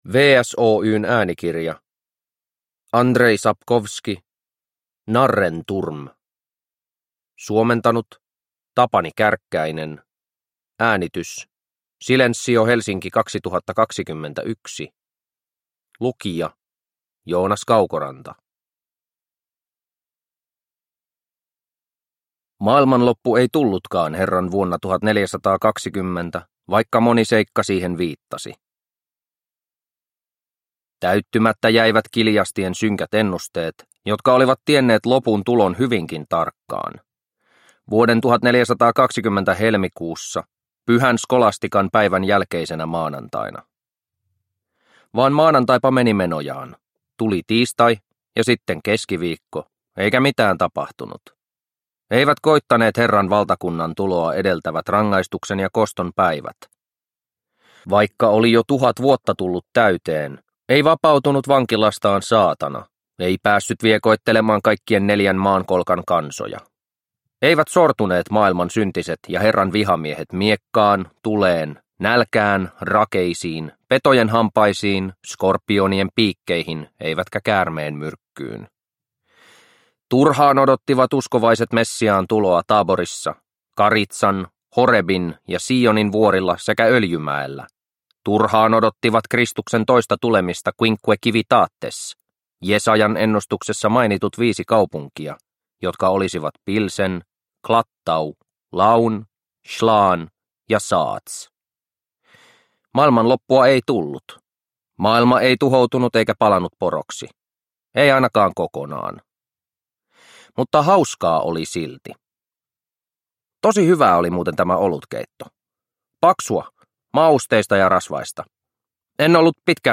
Narrenturm – Ljudbok – Laddas ner